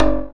percussion 56.wav